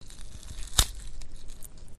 Descarga de Sonidos mp3 Gratis: remover ojo.
eyeball-burst.mp3